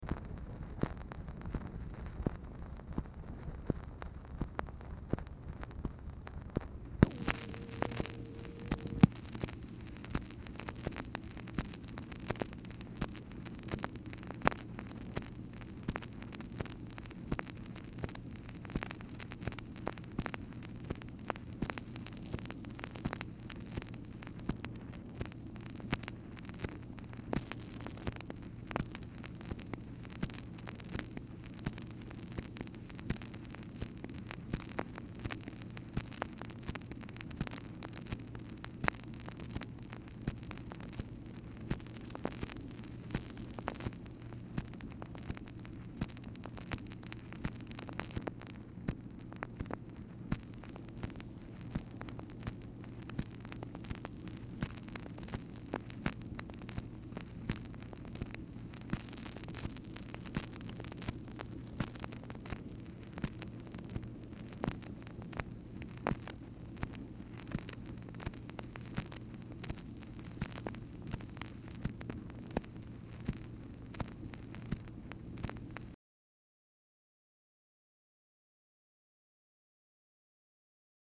Telephone conversation # 2473, sound recording, MACHINE NOISE, 3/11/1964, time unknown | Discover LBJ
Format Dictation belt
Speaker 2 MACHINE NOISE